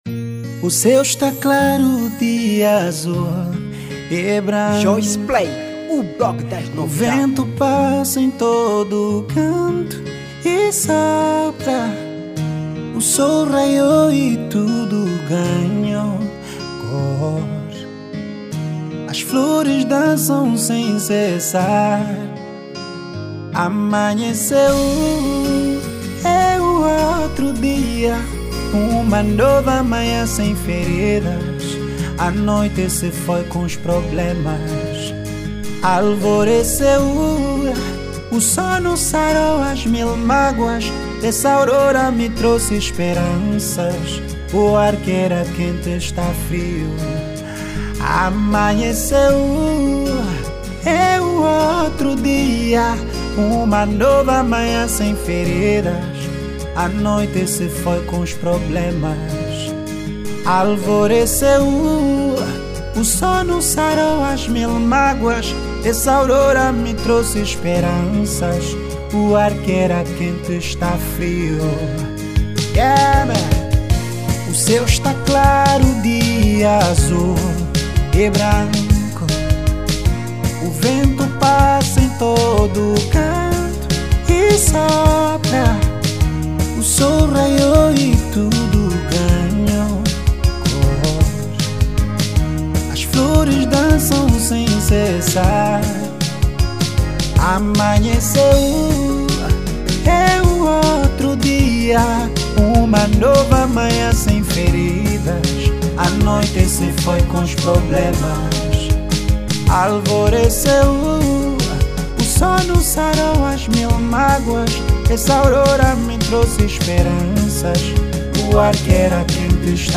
Categoria: R&B/Raggae